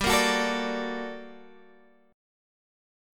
G Minor Major 9th